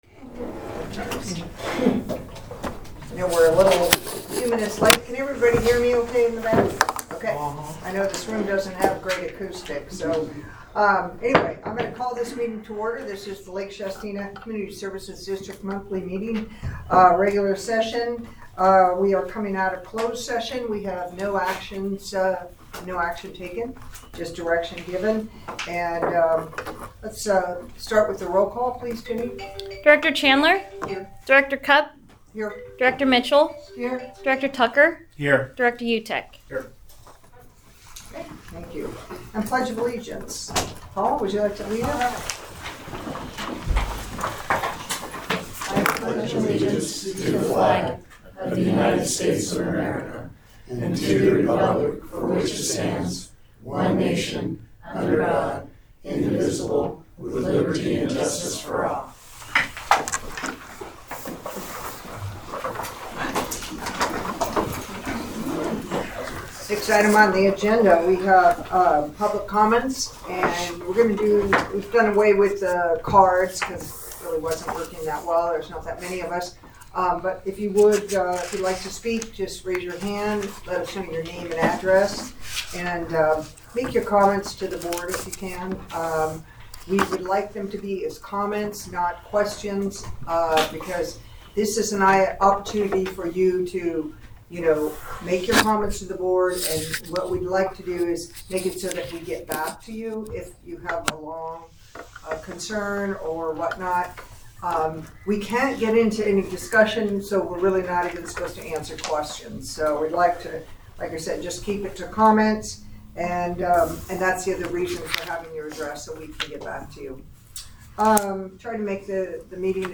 The LSCSD Board of Directors meets monthly on the third Wednesday at 1:00 p.m. at the Administration Building.
Board Meeting